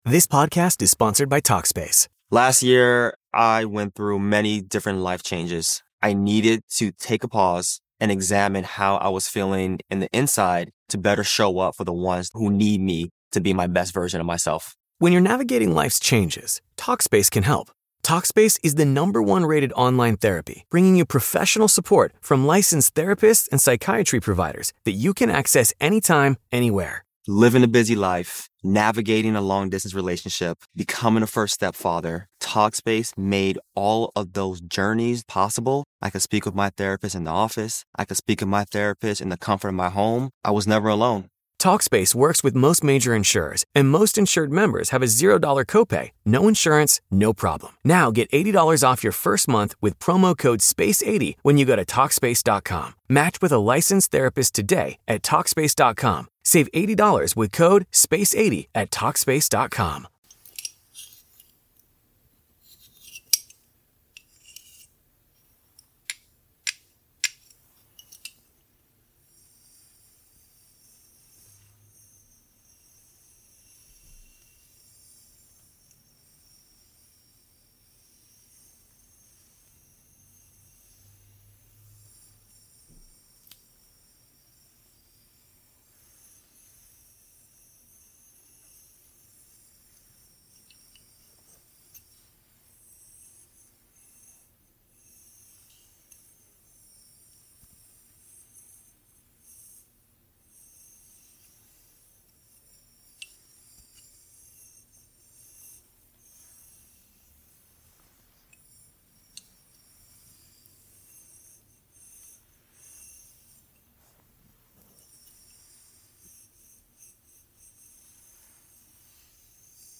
Smooth Hair Tracing & Gentle Movements for Comfort
Our carefully curated episodes feature soothing whispers, gentle tapping, and immersive binaural sounds designed to calm your mind, improve sleep, and bring balance to your day.
From soft-spoken affirmations to delicate hand movements, we blend ambient triggers and immersive soundscapes to create a space where you can experience the full effects of ASMR.